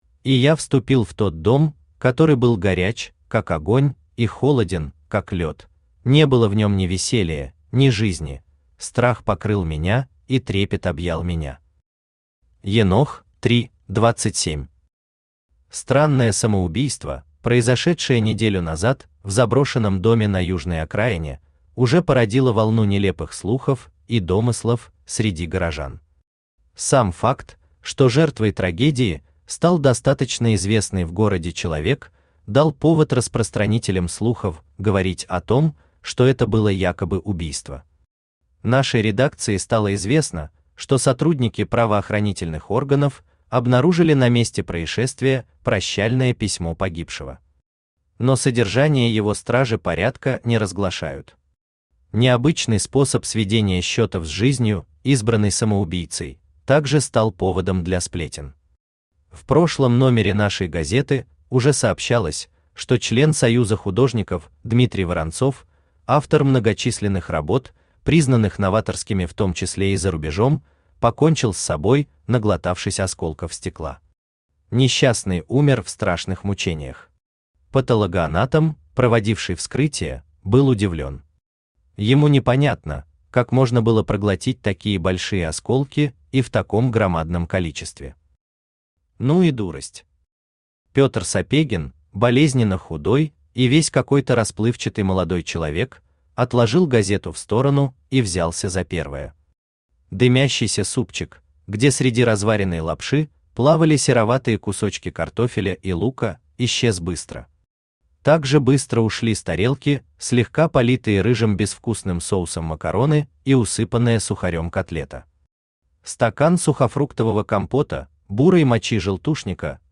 Аудиокнига Дом стеклянных призраков | Библиотека аудиокниг
Aудиокнига Дом стеклянных призраков Автор Владислав Георгиевич Тихонов Читает аудиокнигу Авточтец ЛитРес.